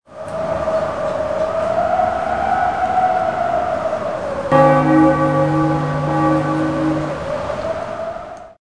PLAY tumbleweed sound effect
tumbleweed.mp3